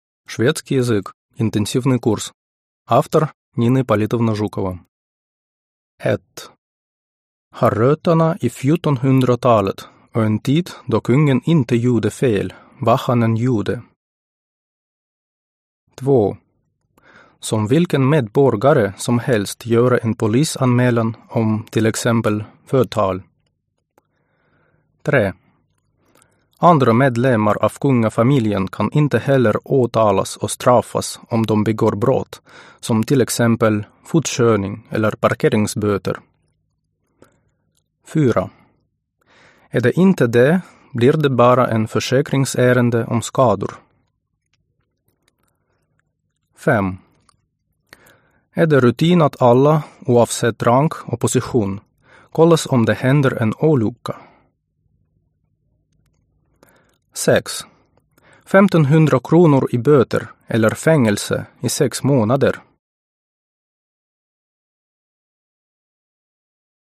Аудиокнига Шведский язык. Интенсивный курс.